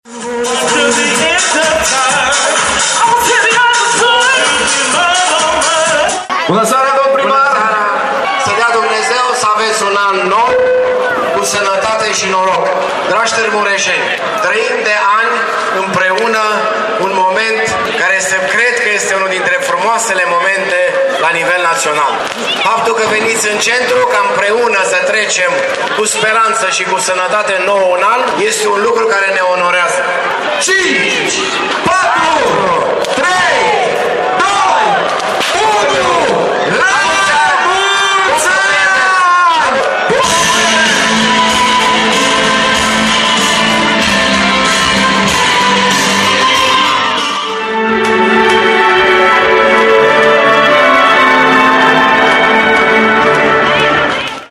Urările pentru noul an au venit din partea primarului, Dorin Florea: